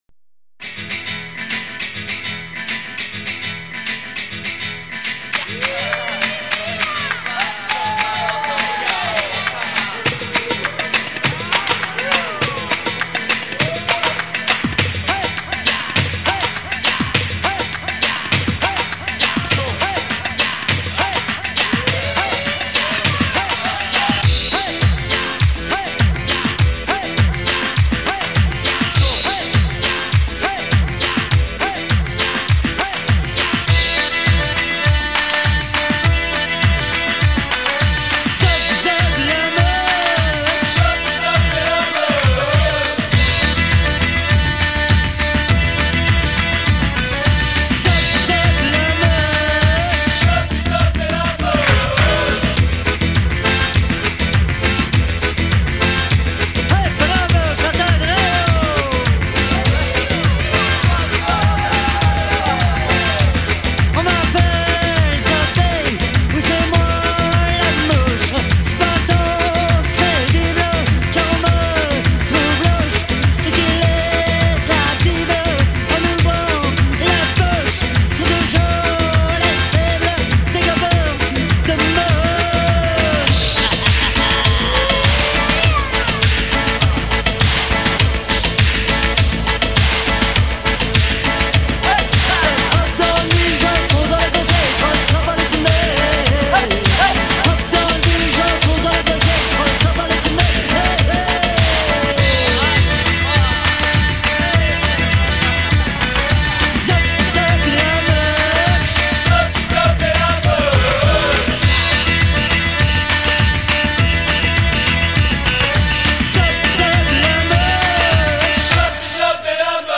folk/punk